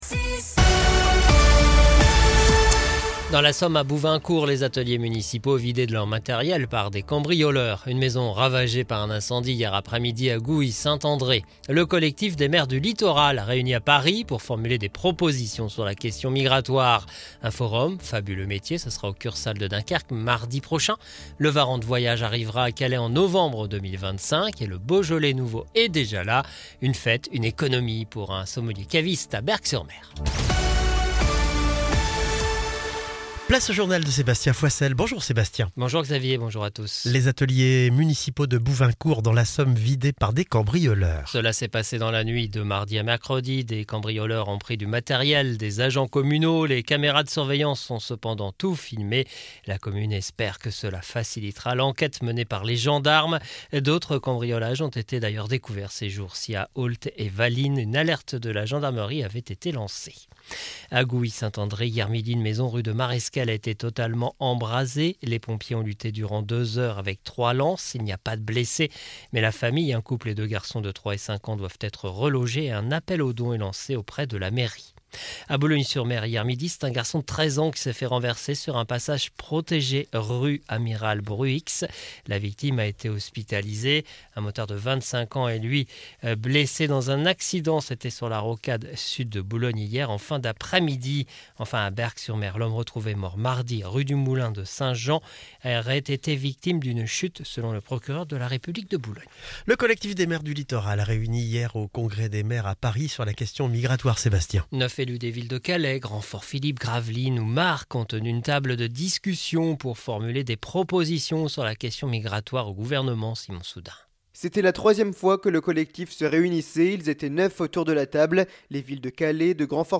Le journal de ce jeudi 21 novembre 2024